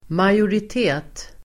Uttal: [majorit'e:t]